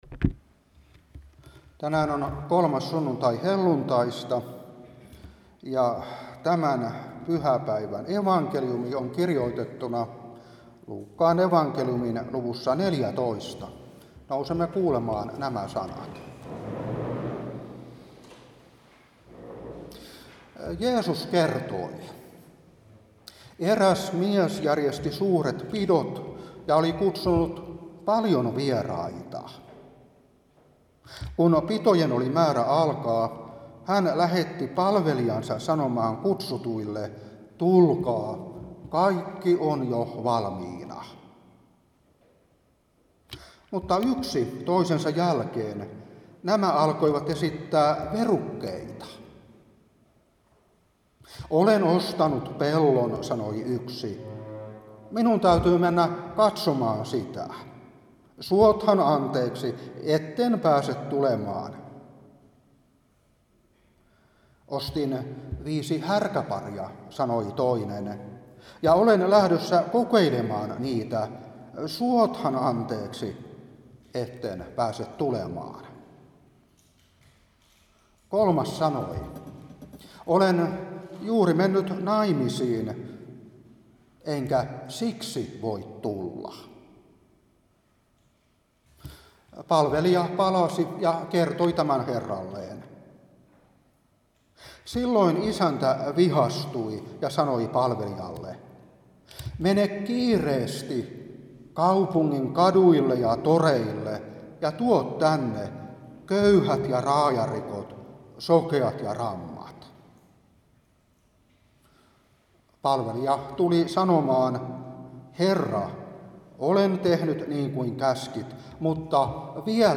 Saarna 2022-6.